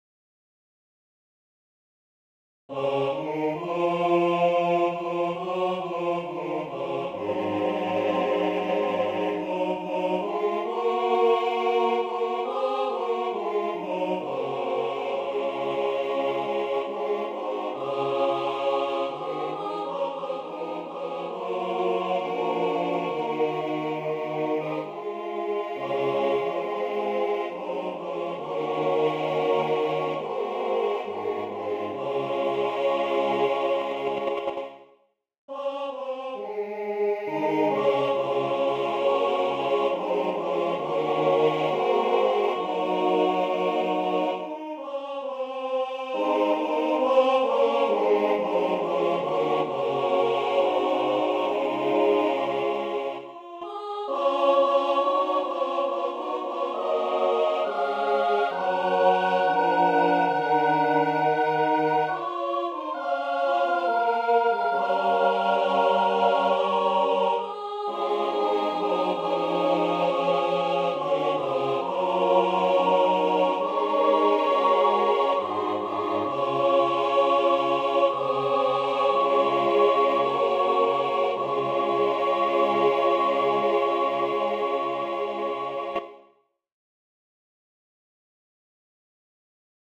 SATB, a cappella